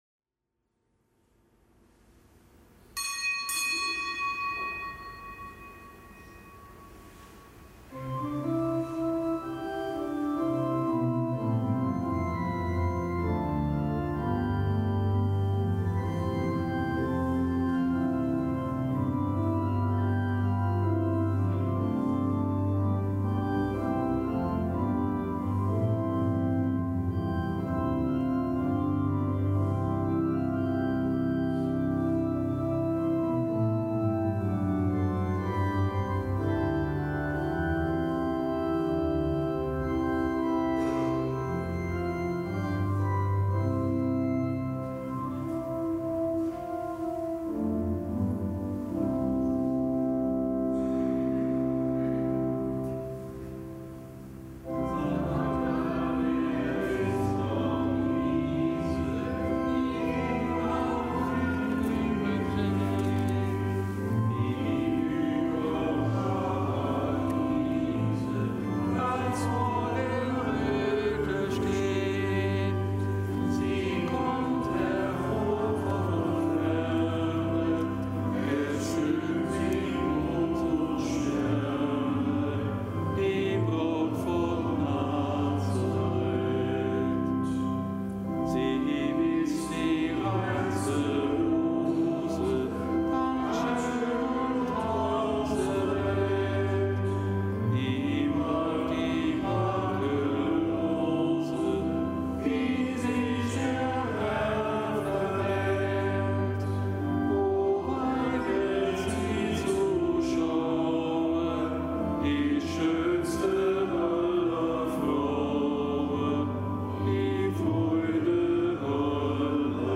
Kapitelsmesse aus dem Kölner Dom am Mittwoch der fünften Woche im Jahreskreis, Nichtgebotener Gedenktag Unsere Liebe Frau in Lourdes.